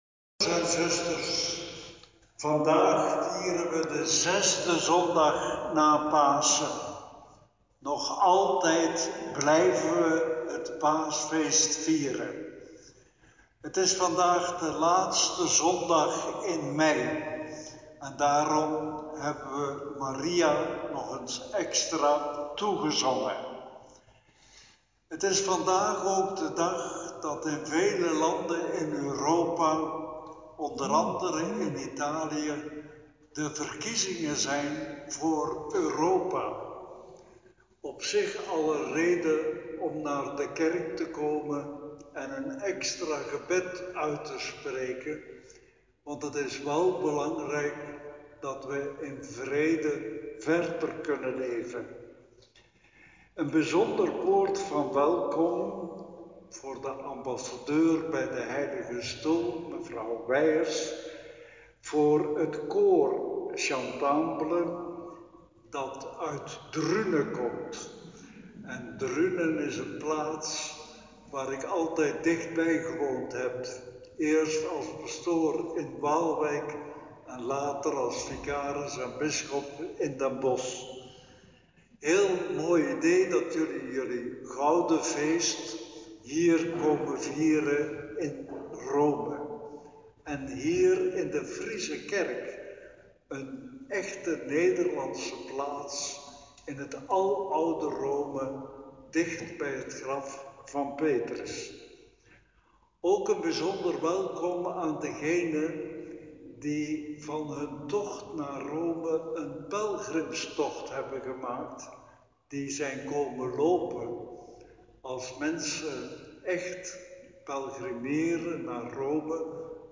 Liturgie; Celebranten, vrijwilligers, gasten; Lezingen, Evangelie; Voorbeden 26 mei 2019; Inleidend woord, preek, woord en gebed voor pelgrims door Mgr. Hurkmans;
Viering 26 mei 2019